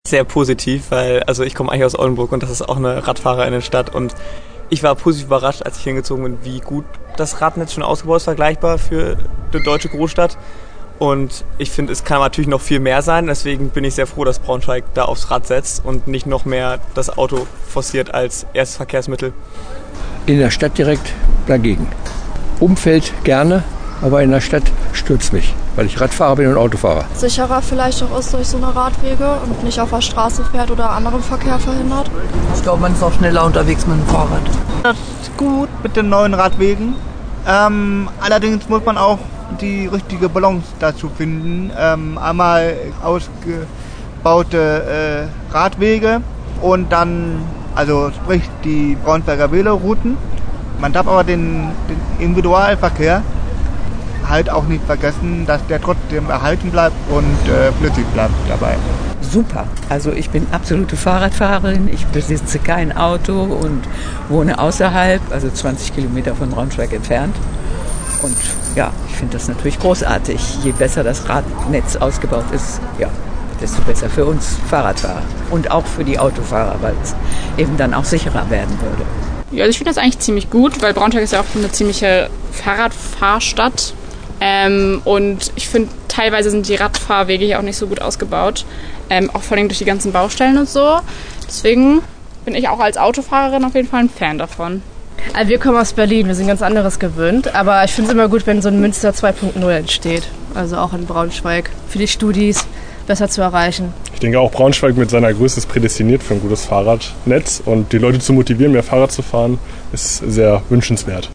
Radfahren soll attraktiver werden: Umfrage zum geplanten Veloroutennetz der Stadt Braunschweig - Okerwelle 104.6
Umfrage-Radverkehr-BS.mp3